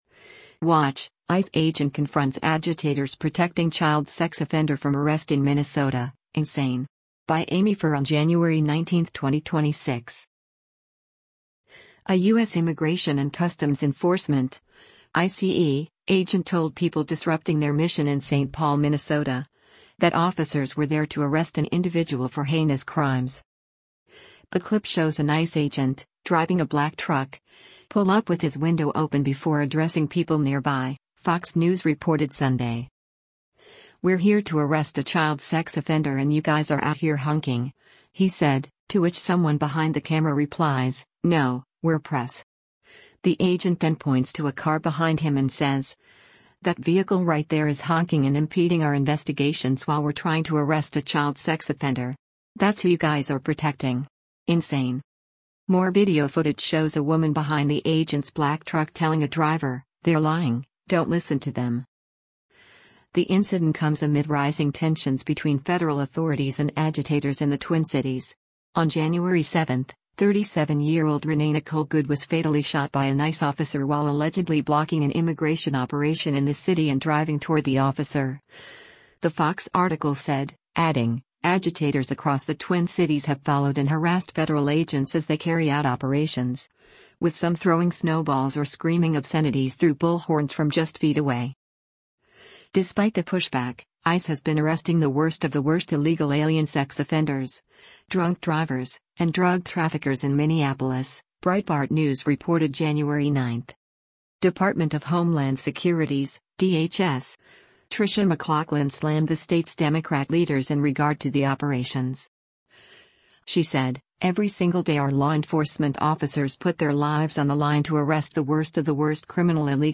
The clip shows an ICE agent, driving a black truck, pull up with his window open before addressing people nearby, Fox News reported Sunday.
More video footage shows a woman behind the agent’s black truck telling a driver, “They’re lying, don’t listen to them.”